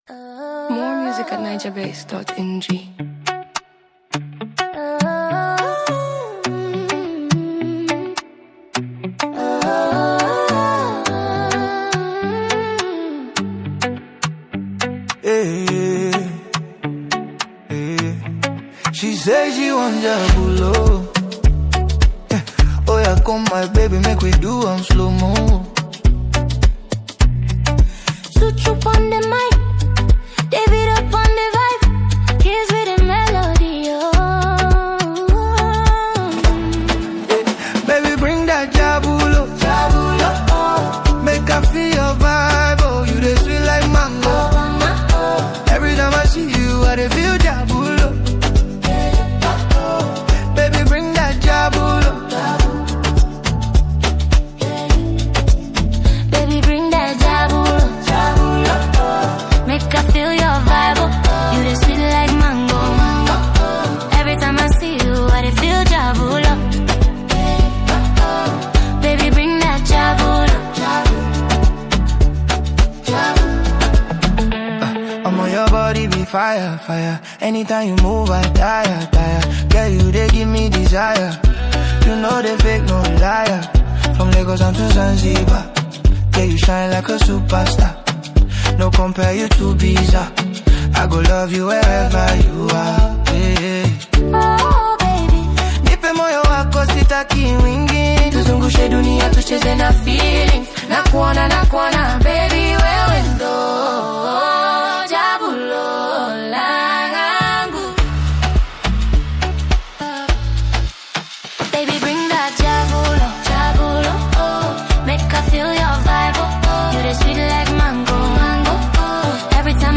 Genre: Afrobeats / Afro-pop